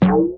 bbounce.wav